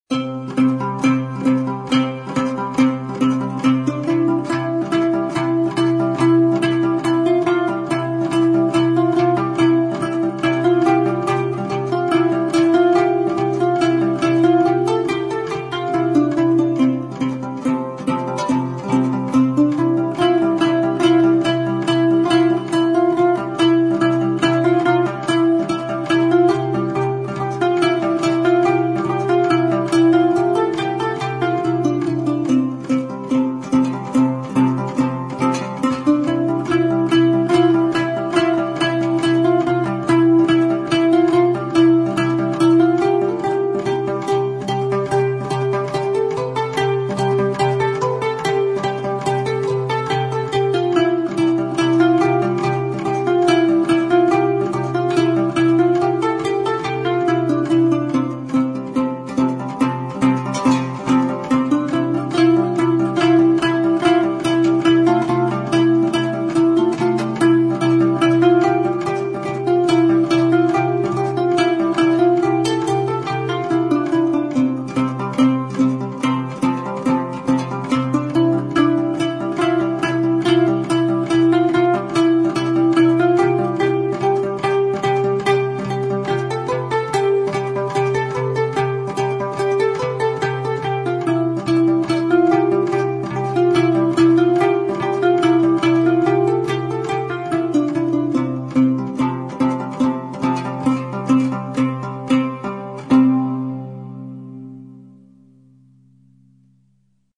народный домбровый кюй.